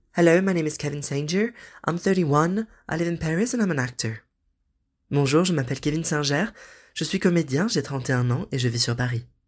Bandes-son
12 - 30 ans - Baryton Contre-ténor